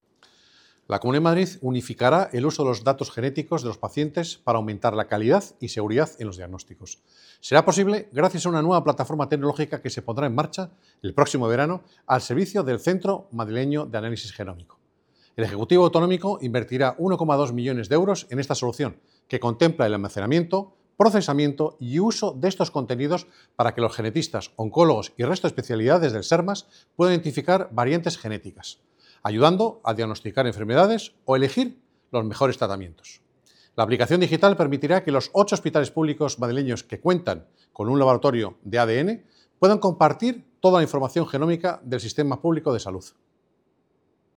Declaraciones del Consejero de Digitalización , Miguel López Valverde, en la página web de la C. de Madrid con la nota de prensa]